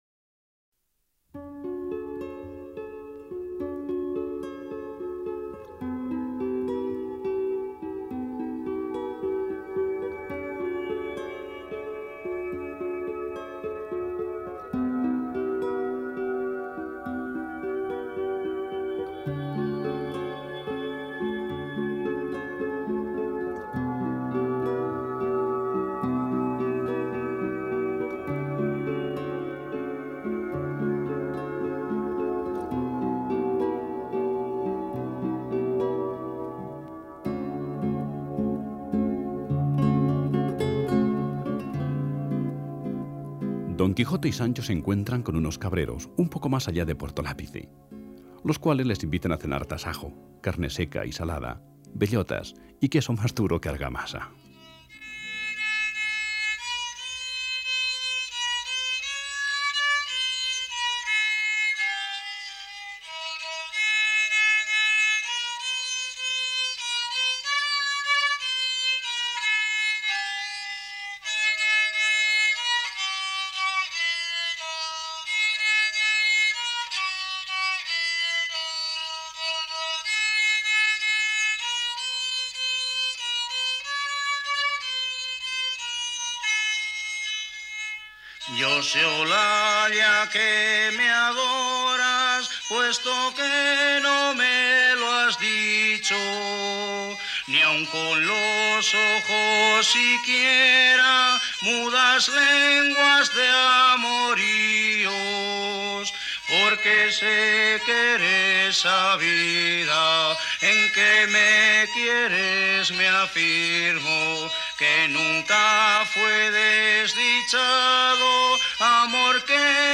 Programa «En un lugar de La Mancha» (R.N.E) grabado en 1988 para un feliz Día de la Radio